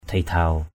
/d̪ʱi-d̪ʱaʊ/ (t.) nhu mì, từ tốn = doux. kamei dhidhaw km] D{D| đàn bà nhu mì = femme douce.
dhidhaw.mp3